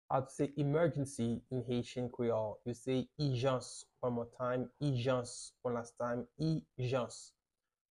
How to say "Emergency" in Haitian Creole - "ijans" pronunciation by a native Haitian tutor
“ijans” Pronunciation in Haitian Creole by a native Haitian can be heard in the audio here or in the video below:
How-to-say-Emergency-in-Haitian-Creole-ijans-pronunciation-by-a-native-Haitian-tutor.mp3